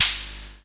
Index of /musicradar/essential-drumkit-samples/Vintage Drumbox Kit
Vintage Open Hat 02.wav